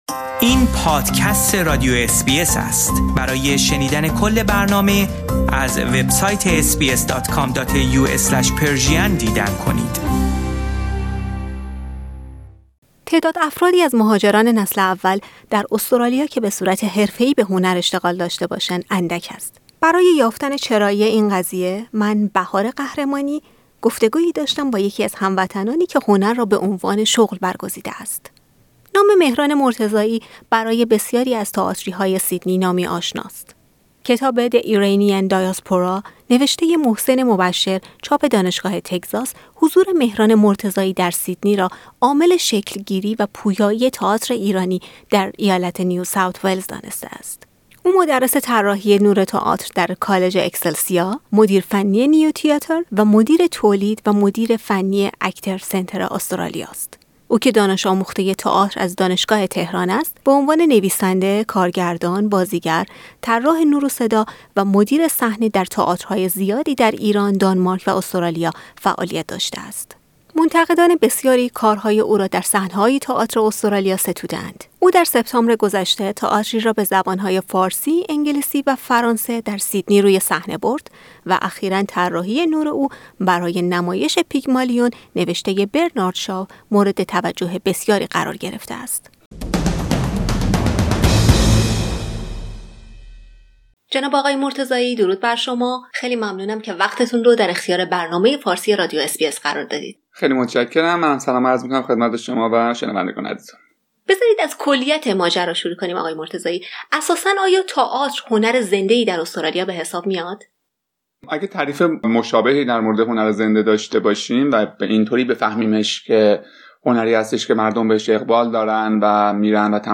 براي یافتن دلیل اين قضيه گفتگويی داشتيم با يكی از هنرمندان ساکن سیدنی كه هنر را به عنوان شغل برگزيده است.